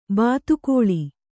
♪ bātu kōḷi